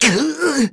Lucias-Vox_Damage_03_kr.wav